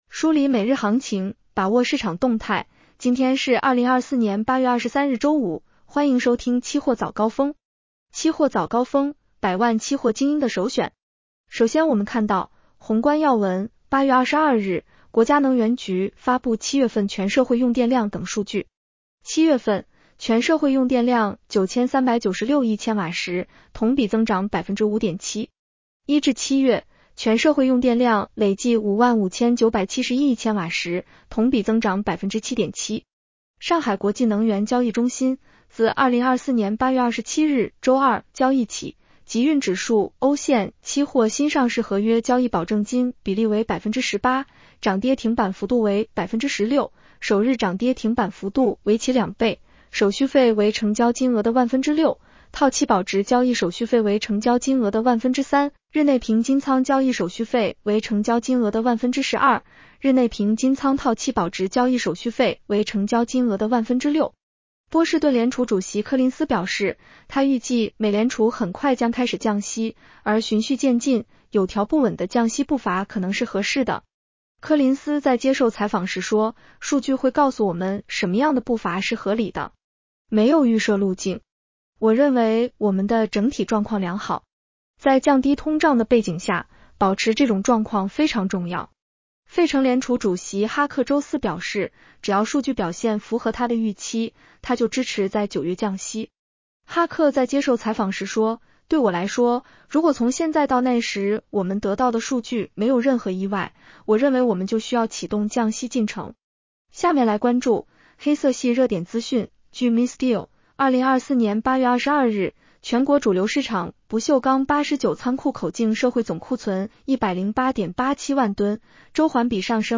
期货早高峰-音频版 女声普通话版 下载mp3 宏观要闻 1. 8月22日，国家能源局发布7月份全社会用电量等数据。